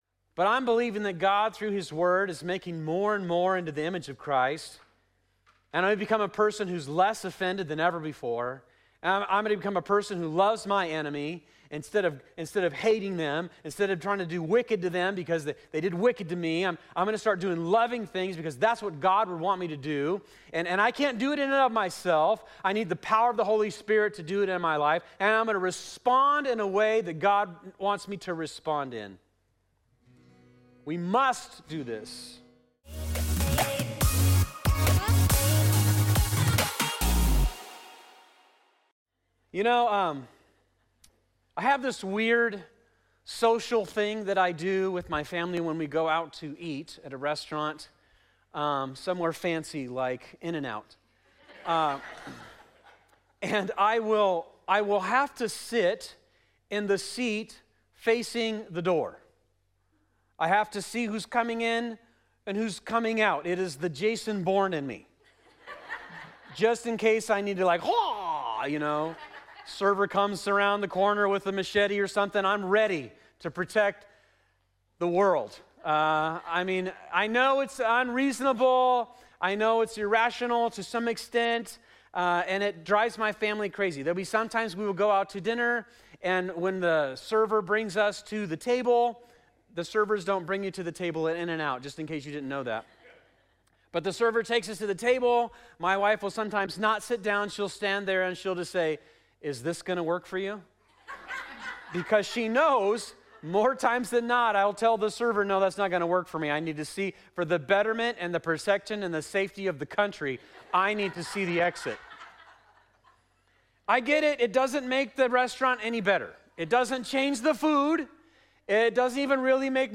2026 It's Complicated Conflict Relationships Sunday Morning "It's Complicated" is our series at Fusion Christian Church on relationships.